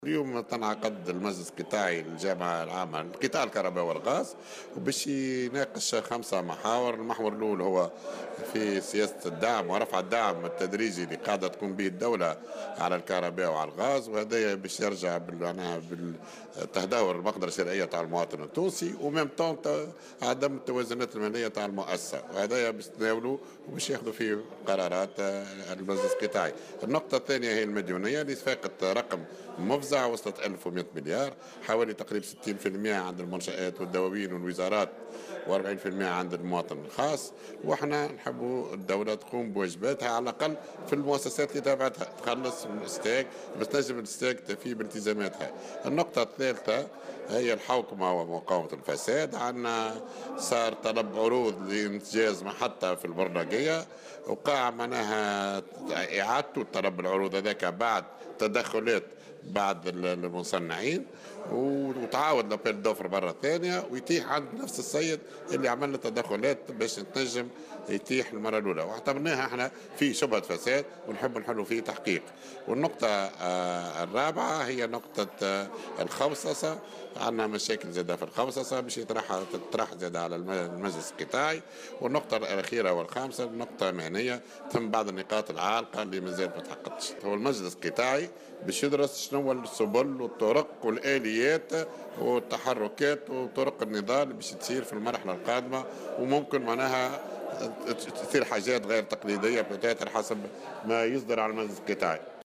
في تصريح لمراسل "الجوهرة أف أم" على هامش افتتاح المجلس القطاعي للجامعة العامة للكهرباء والغاز